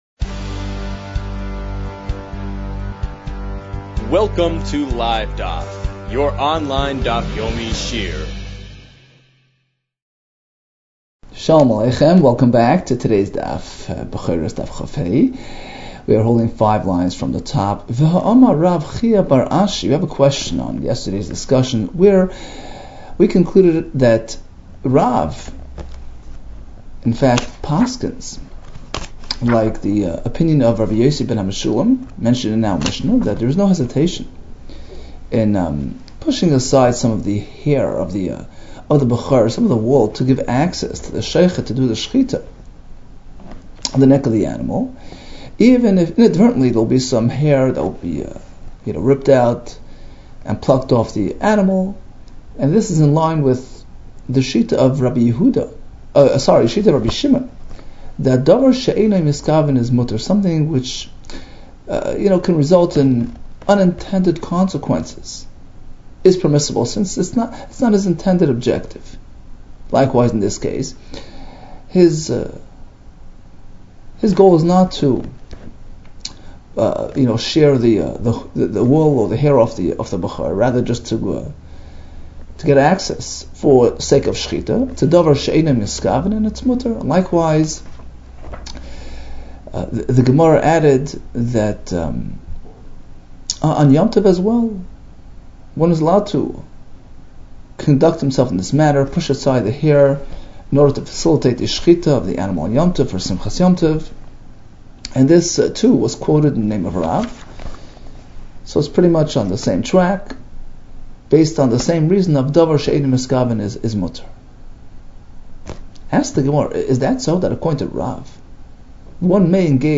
Bechoros 24 - בכורות כד | Daf Yomi Online Shiur | Livedaf